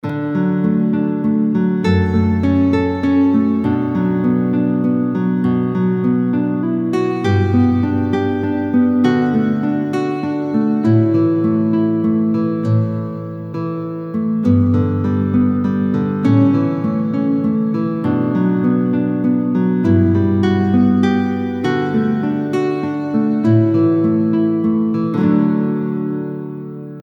Envie de jouer la mélodie principale en fingerpicking ?
Capodastre : 2ᵉ case